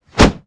zombie_swing_2.wav